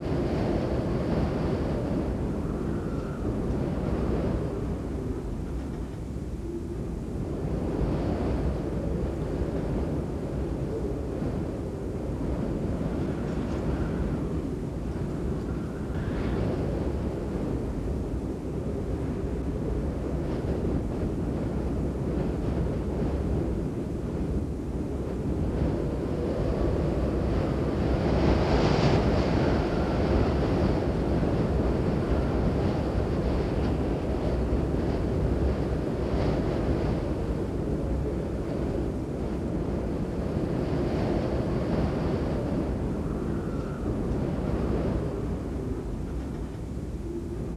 Desert_Wind__Whistle.R.wav